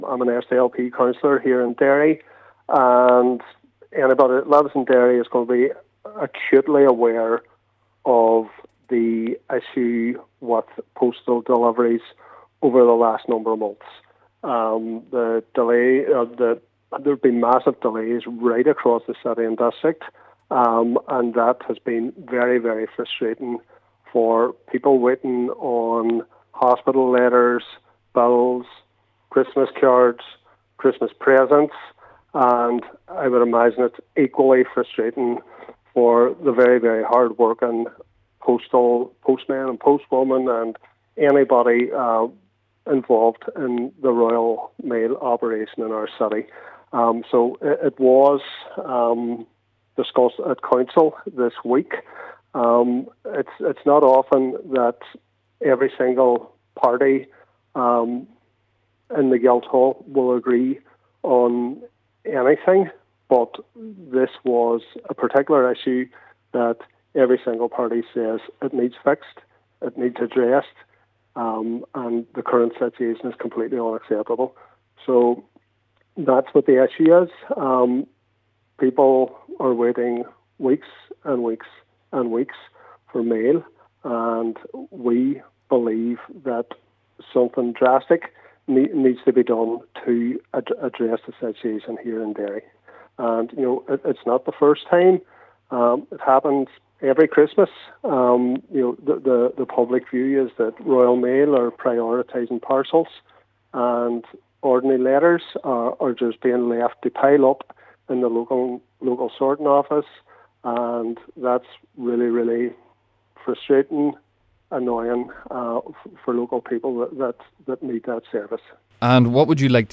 Cllr Rory Farrell, who represents the Ballyarnett area has explained the current issue: